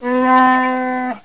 Grunts6 (28 kb)